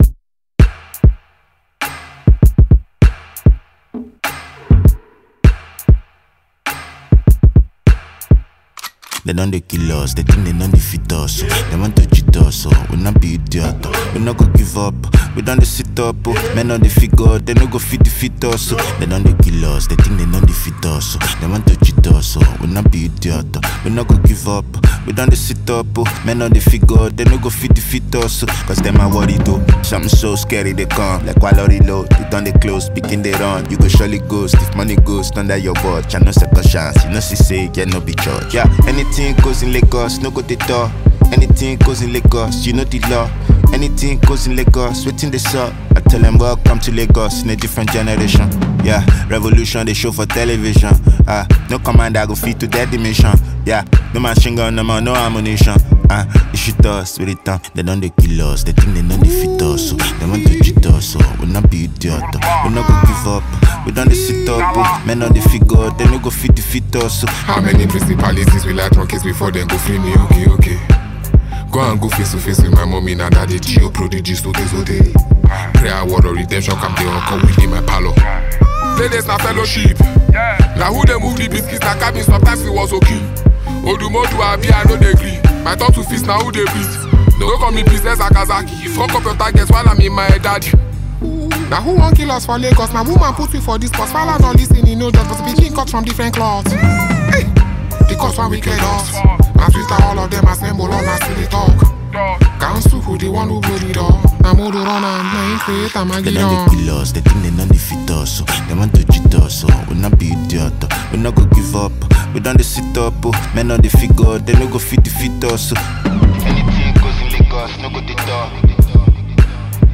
Nigerian rapper and songwriter